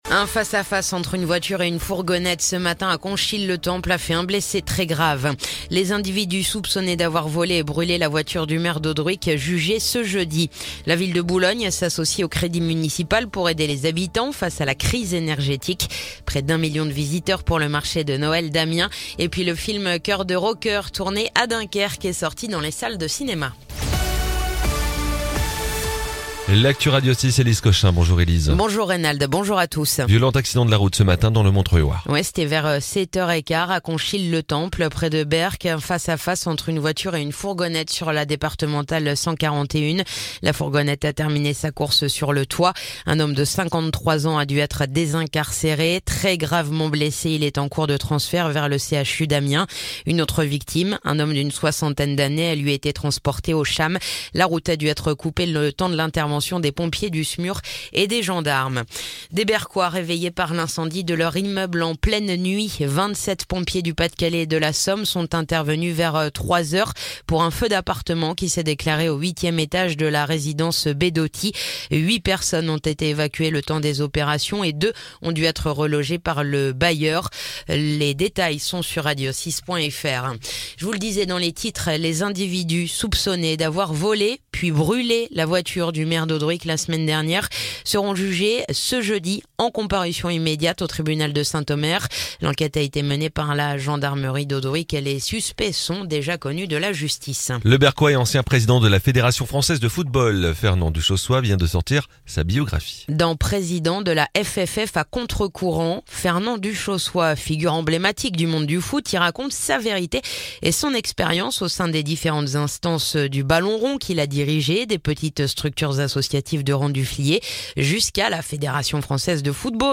Le journal du jeudi 29 décembre